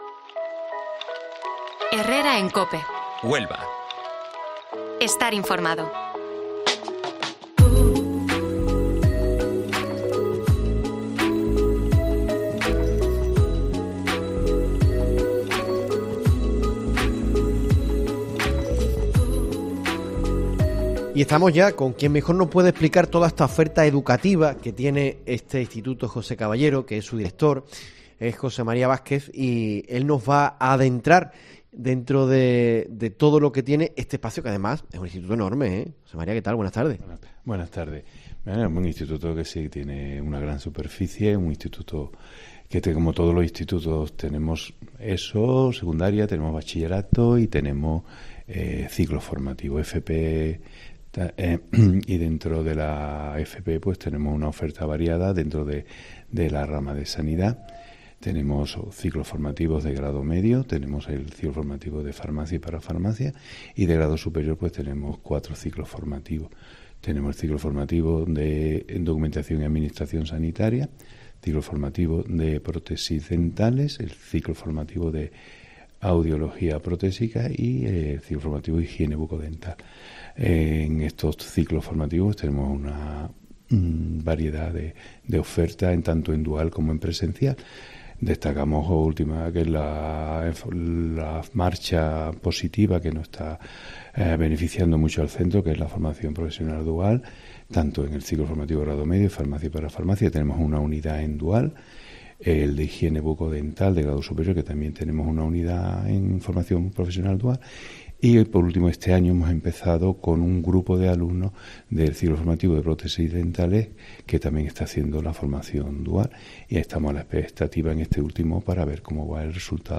Herrera en COPE Huelva desde el IES José Caballero